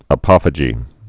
(ə-pŏfə-jē)